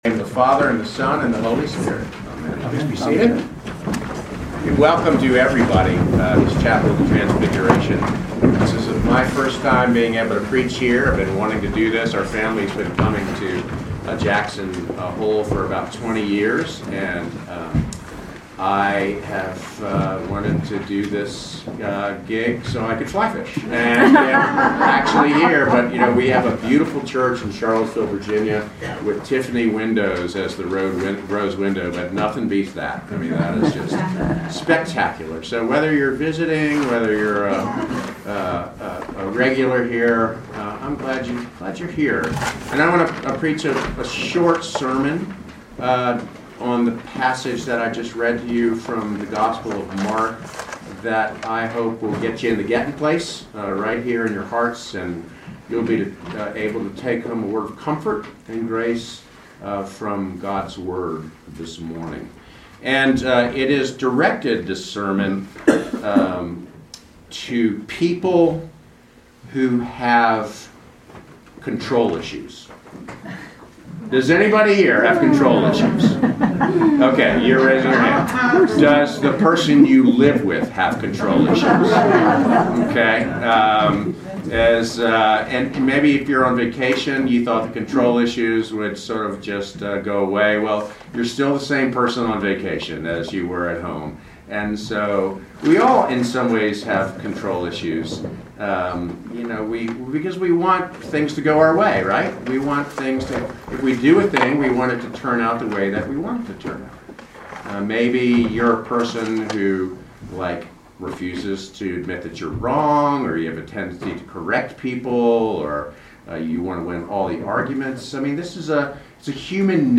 Proper 6 at the Chapel of the Transfiguration
Sermons from St. John's Episcopal Church